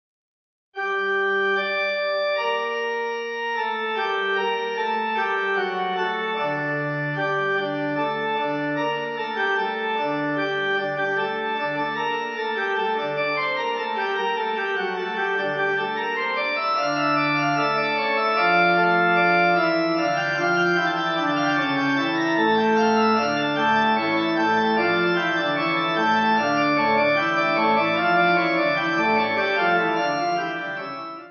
Organ
Easy Listening   Gm